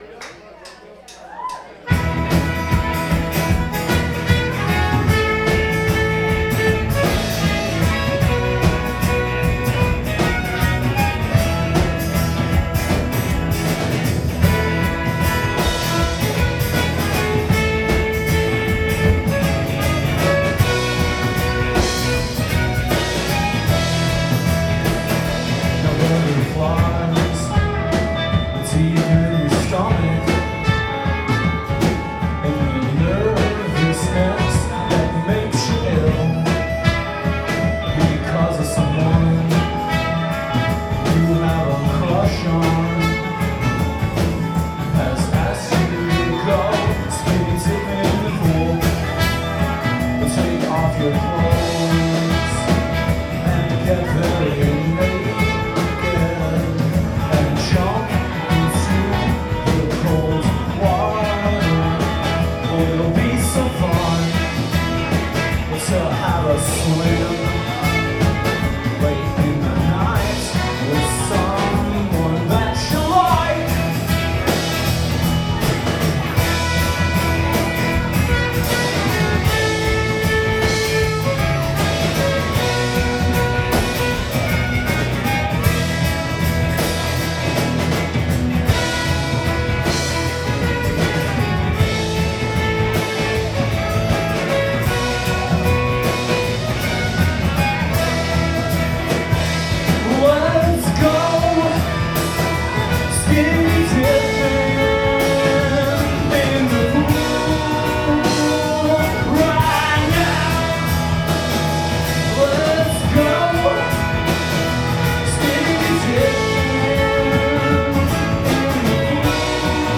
Schuba's Tavern
full band